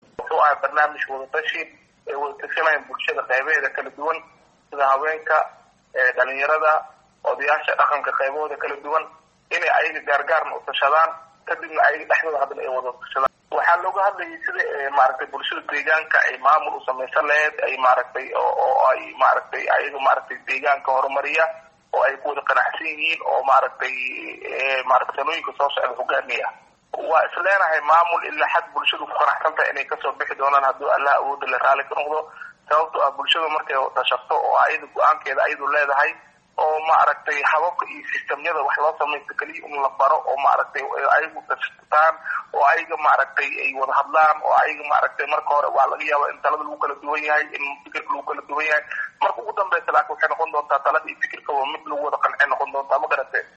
Gudoomiye ku xigeenka arimaha dhaqaalaha gobolka shabelaha hoose Cabdifataax Cabdulle Yusuf oo la hadley warbaahinta ayaa ayaa ka hadley dhismo maamul daageeneed oo beryahan ka socdey degmada baraawe.